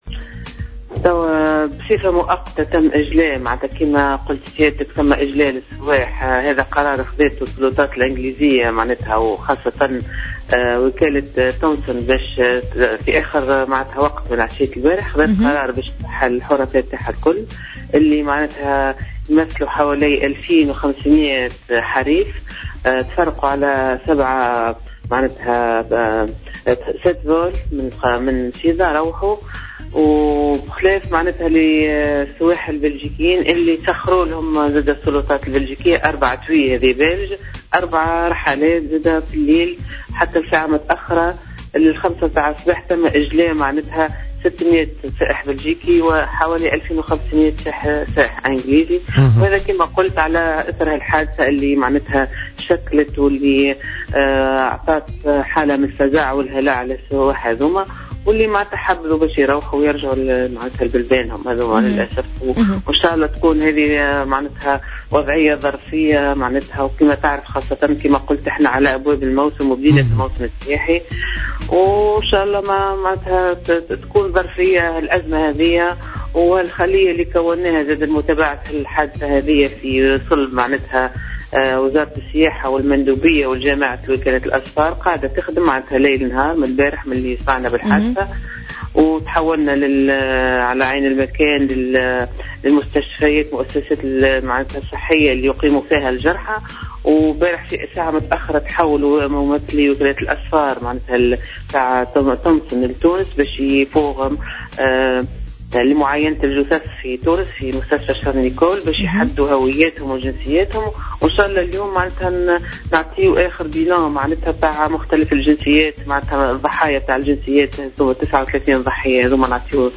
أكدت المندوبة الجهوية للسياحة بسوسة، سلوى القادري اليوم السبت في تصريح ل"جوهرة أف أم" أن 2500 سائح أنجليزي و600 بلجيكي غادروا مدينة سوسة باتجاه بلدانهم إثر الهجوم الذي استهدف أمس الجمعة نزلا سياحا بأحد نزل المنطقة السياحية بالقنطاوي.